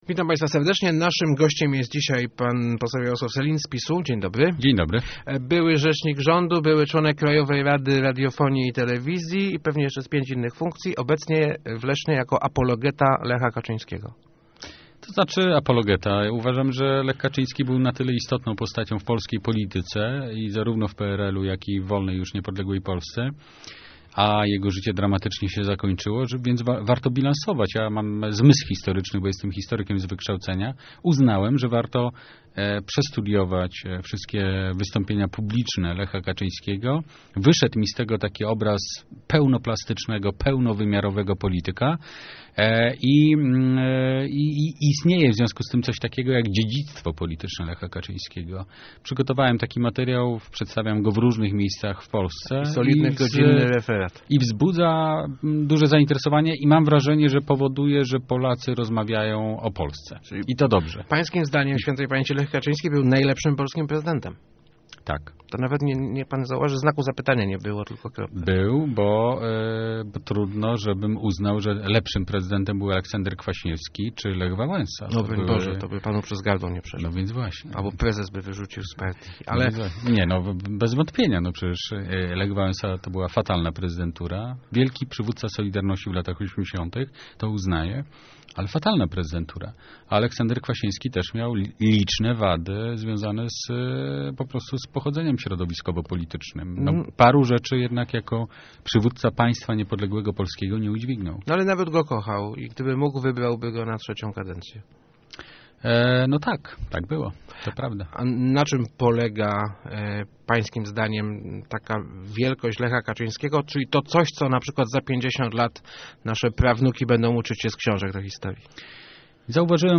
Najważniejszym dokonaniem Lecha Kaczyńskiego była mobilizacja prezydentów w obronie Gruzji, która uratowała ten kraj - mówił w Rozmowach Elki poseł PiS Jarosław Sellin. Jego zdaniem ważne jest rówież dziedzictwo myśli propaństwowej zmarłego prezydenta.